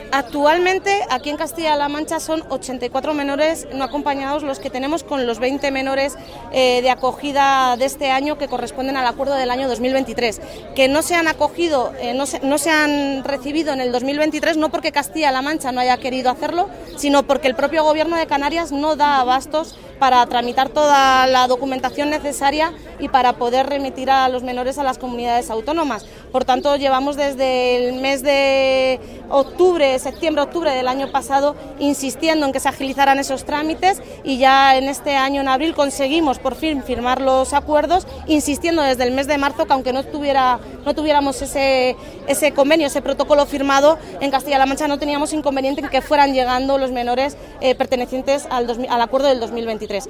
El Gobierno de Castilla-La Mancha atenderá la acogida de menores extranjeros no acompañados e insta a las administraciones competentes a la agilidad en los traslados. Así lo ha manifestado la consejera de Bienestar Social, Bárbara García Torijano a preguntas de los medios de comunicación sobre el Pleno de la Conferencia Sectorial de Infancia y Adolescencia que se celebró en Santa Cruz de Tenerife, el pasado miércoles.